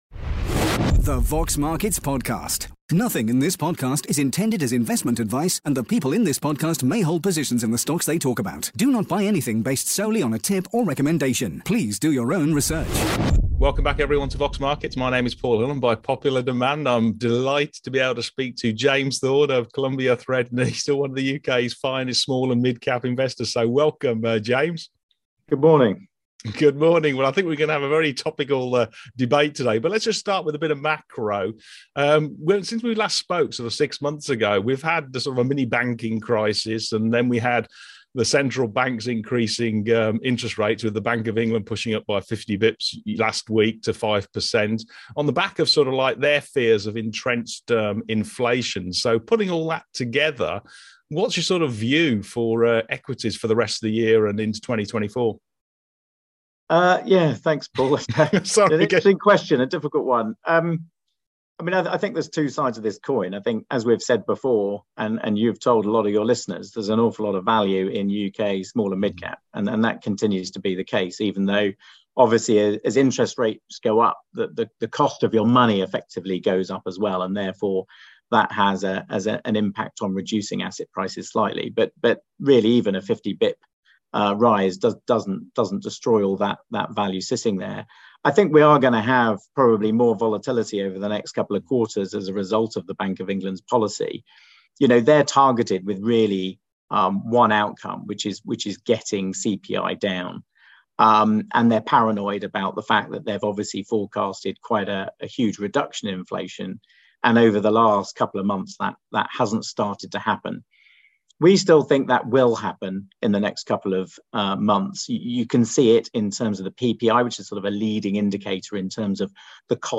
Q&A